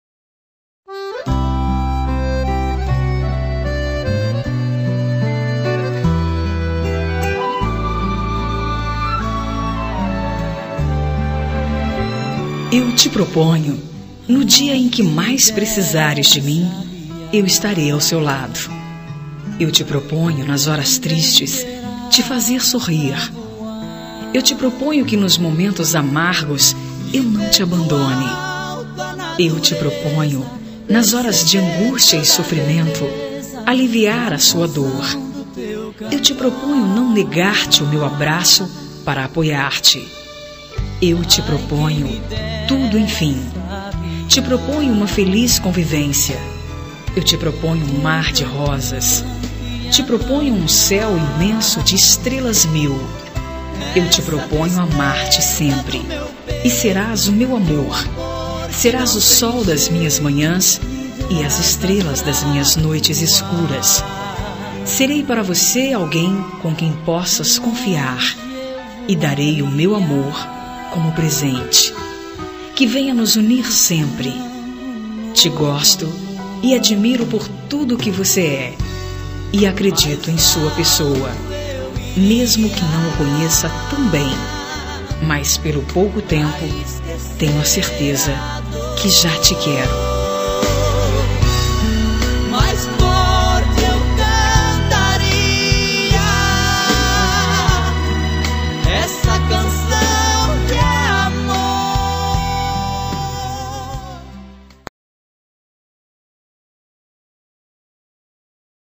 Telemensagem de Conquista – Voz Feminina – Cód: 140107